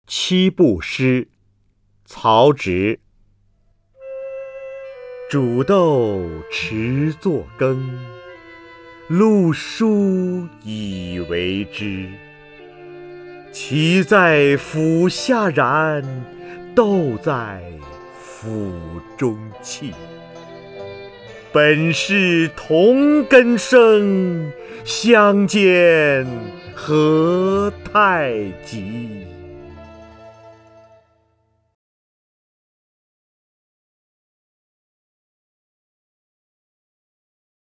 瞿弦和朗诵：《七步诗》(（三国）曹植) （三国）曹植 名家朗诵欣赏瞿弦和 语文PLUS
（三国）曹植 文选 （三国）曹植： 瞿弦和朗诵：《七步诗》(（三国）曹植) / 名家朗诵欣赏 瞿弦和